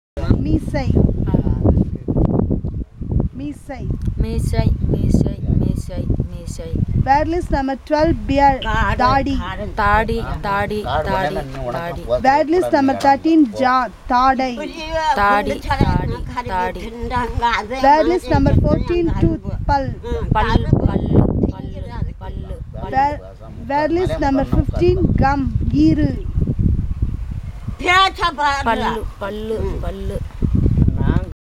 Elicitation of words about human body parts - Part 3